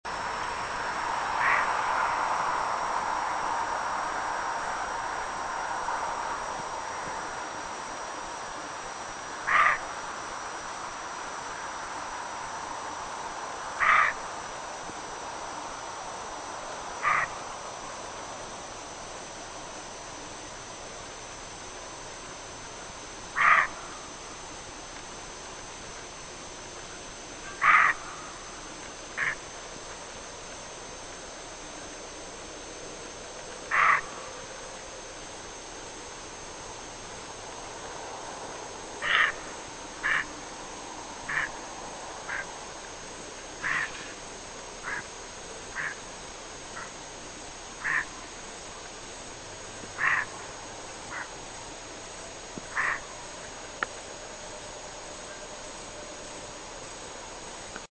I lay in the tent trying to fall asleep as things rustled around outside. This was fairly normal, but as the rustling became louder and closer I began to wonder if it was rats looking for food.
By now I could hear the footsteps getting closer - obviously it was a larger animal outside, and I felt my legs going tense with nerves.
beast.mp3